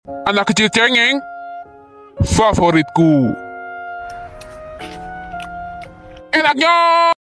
Dubbing Indonesia